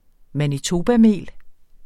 Udtale [ maniˈtoːbaˌmeˀl ]